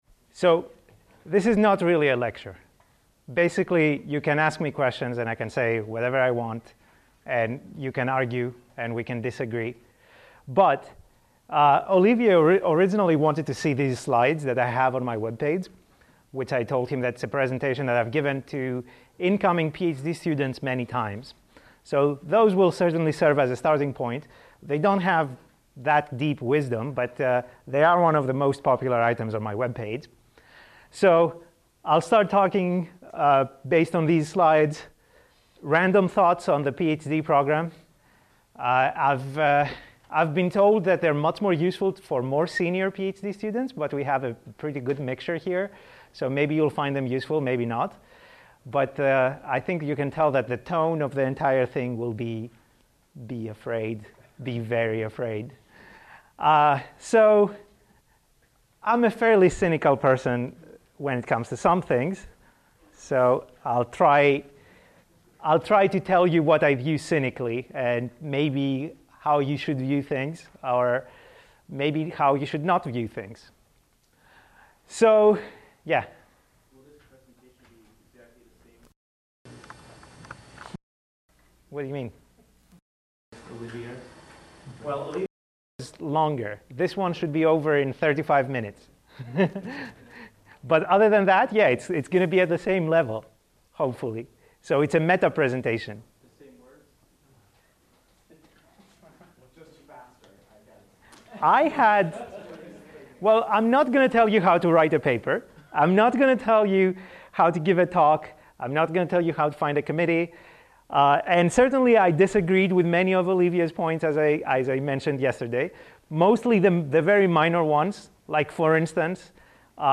The program consists of 80 minute lectures presented by internationally recognized leaders in programming languages and formal reasoning research.